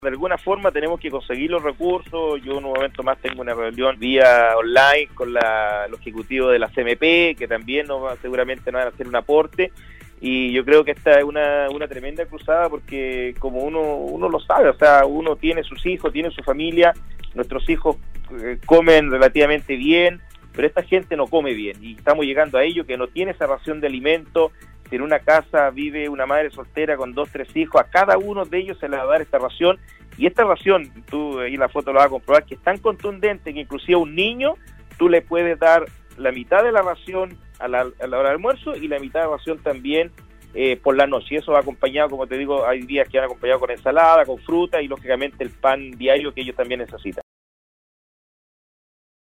La tarde de este miércoles, el alcalde de Vallenar, Cristian Tapia sostuvo un contacto telefónico en la emisión del noticiero de Nostálgica donde se refirió a las raciones de almuerzos que se están entregando a las familias más vulnerables de la comuna.